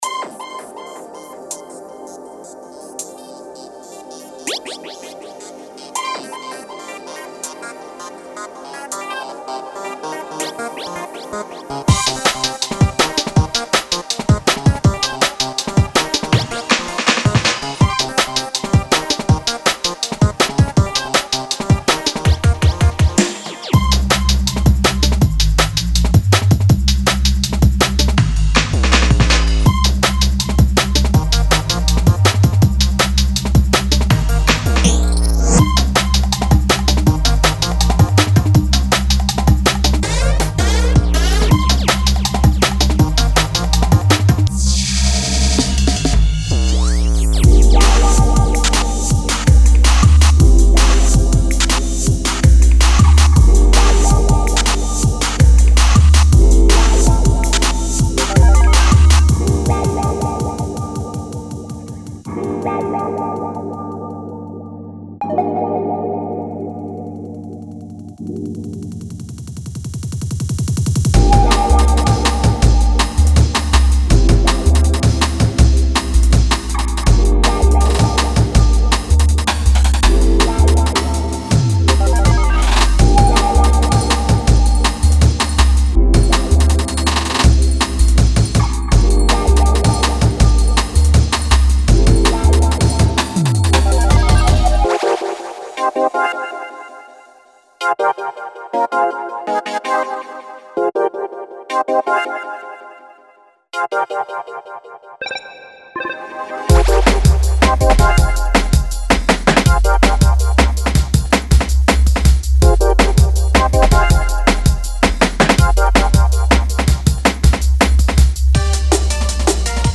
Genre:Jungle
160〜167BPMで展開されるループを収録しており、ドラムンベースのあらゆる探求に最適なサウンドが揃っています。
豊富なコードボイシングを用いることで、動きとヴァイブに満ちた、厚みのあるリッチなパッドが生み出されています。
デモサウンドはコチラ↓